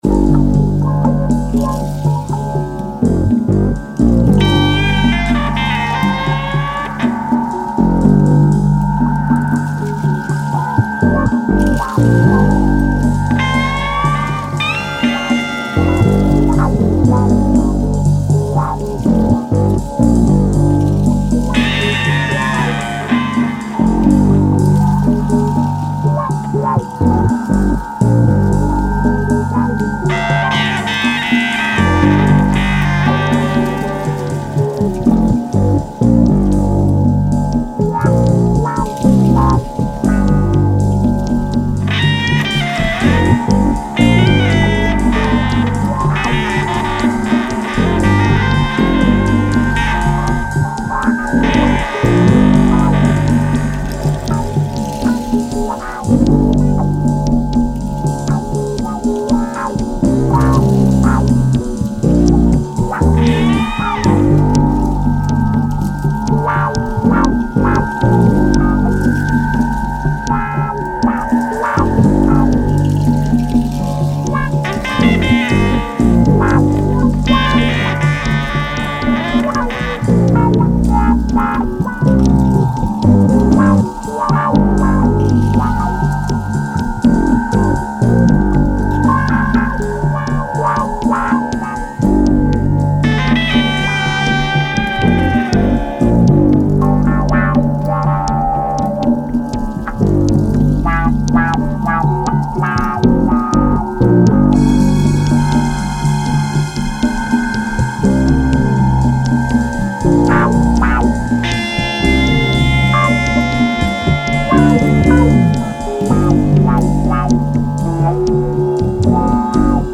Electroacoustic duo
Electronix Funk Jazz Ambient